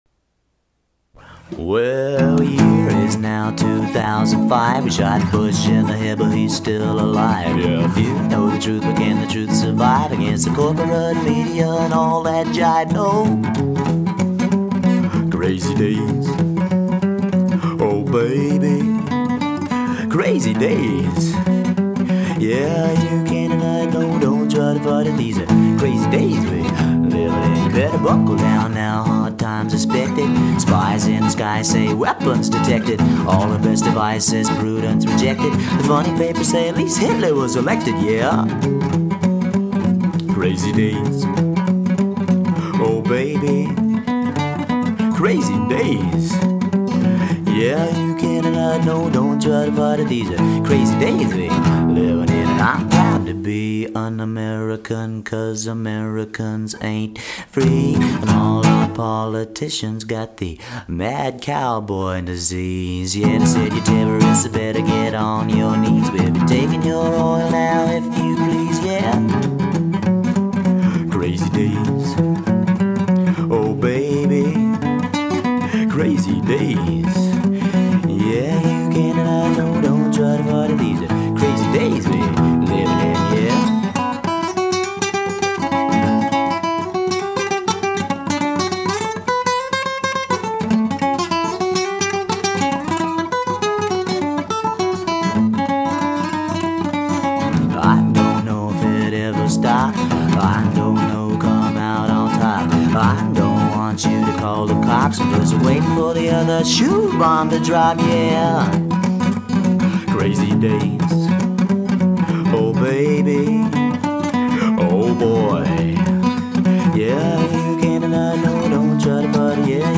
Demos and live songs: